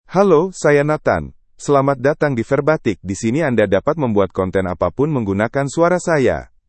NathanMale Indonesian AI voice
Nathan is a male AI voice for Indonesian (Indonesia).
Voice sample
Listen to Nathan's male Indonesian voice.
Male
Nathan delivers clear pronunciation with authentic Indonesia Indonesian intonation, making your content sound professionally produced.